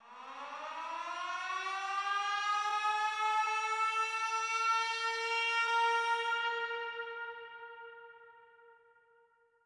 Twelve Siren.wav